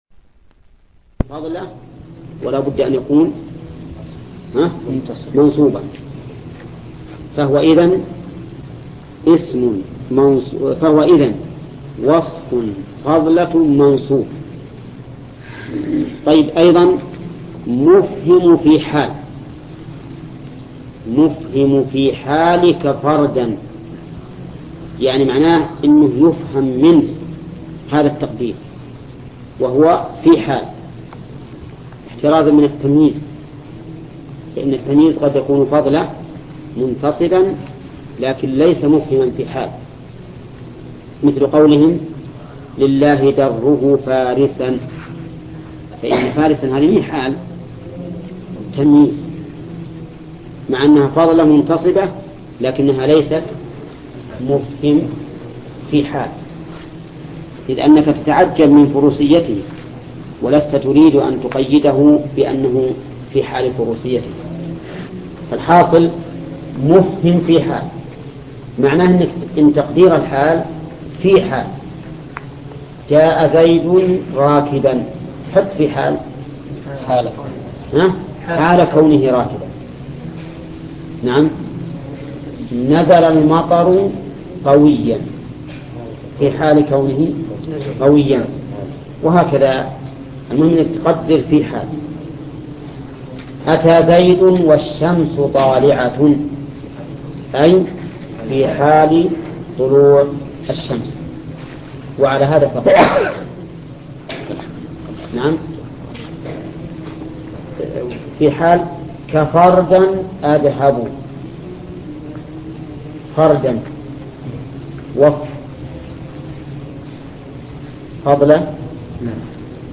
ألفية ابن مالك شرح الشيخ محمد بن صالح العثيمين الدرس 79